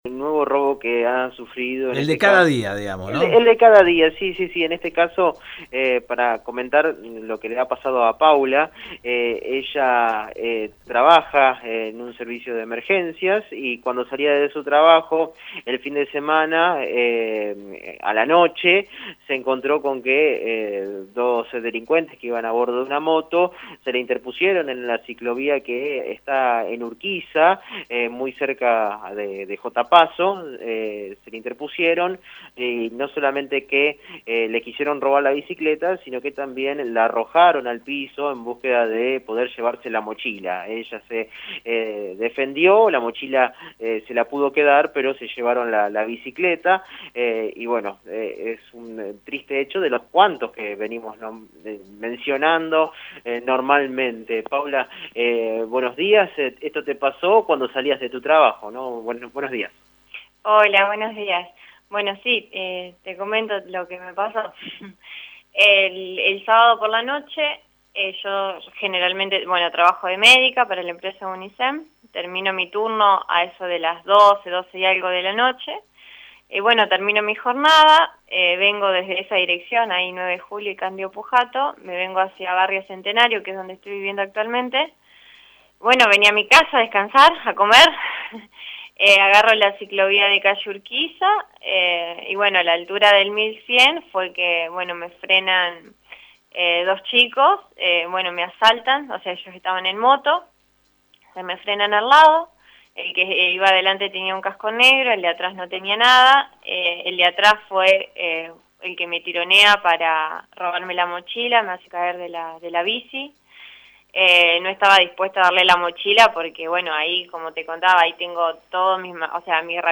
En dialogo con el móvil de Radio EME